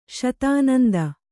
♪ śatānanda